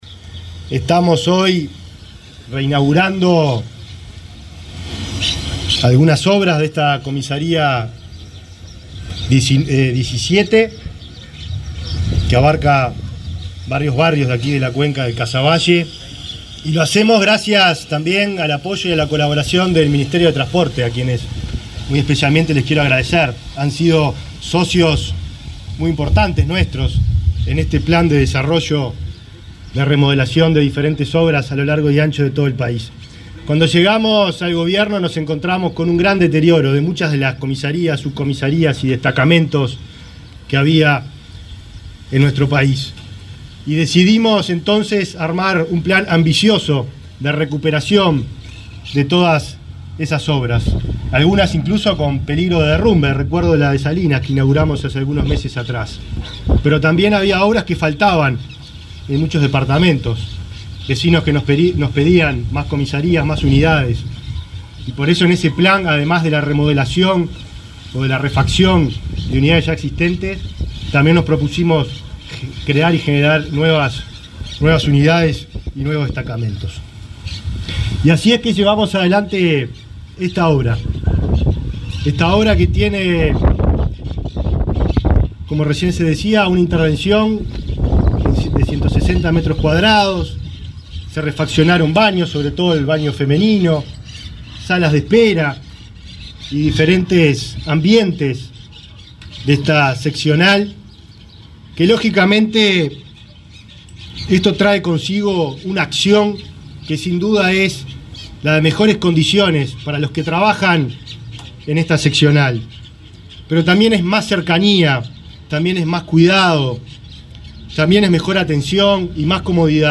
Palabras del ministro del Interior, Nicolás Martinelli
Este viernes 2, el ministro del Interior, Nicolás Martinelli, se expresó durante la presentación de las obras de remodelación de la seccional 17.ª de